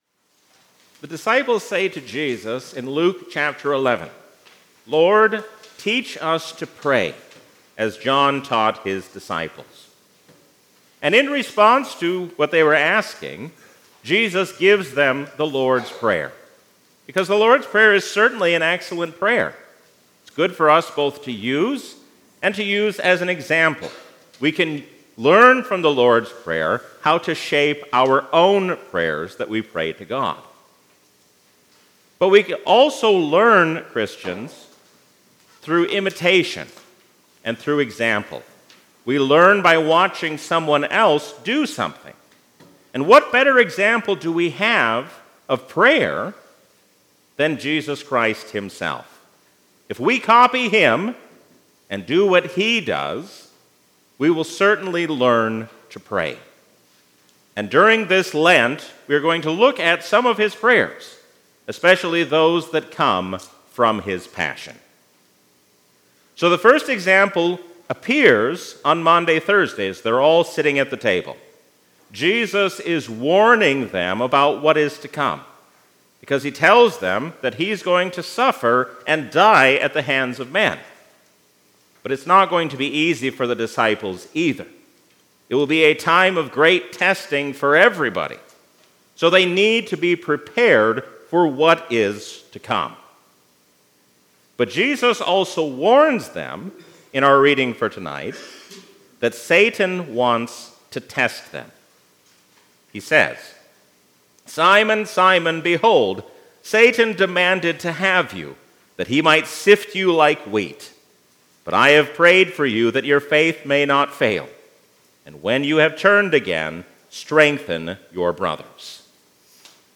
A sermon from the season "Lent 2023." Jesus teaches us what it means to seek after the will of God, even as we pray for things to be taken away.